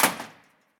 Abrir la puerta de un plató de televisión
Sonidos: Acciones humanas